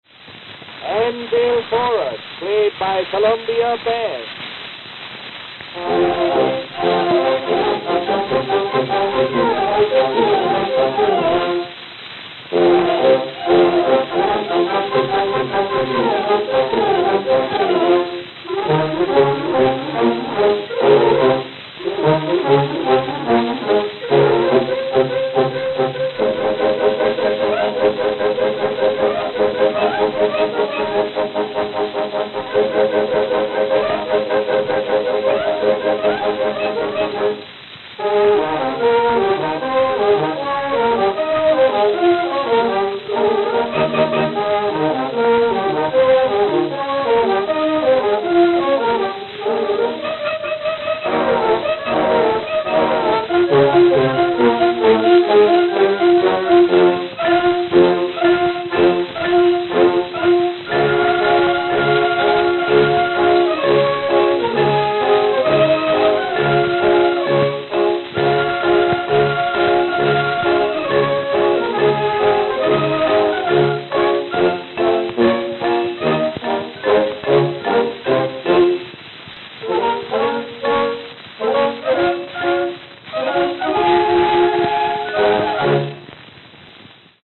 Columbia 7-Inch Records
They also lagged behind in sound quality and in A&R. Somewhat the child of Berliner, Victor had the head start on disc technology and its G&T affiliate in Europe quickly made talent important to the company as well.
Their early discs actually had great bass response, which oddly decreased a bit as time went on.
Columbia Band
New York, New York